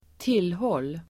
Uttal: [²t'il:hål:]